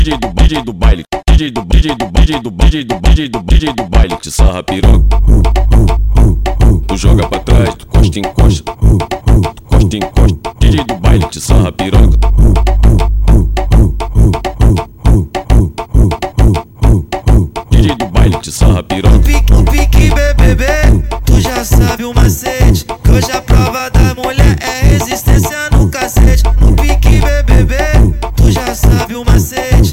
Жанр: Фанк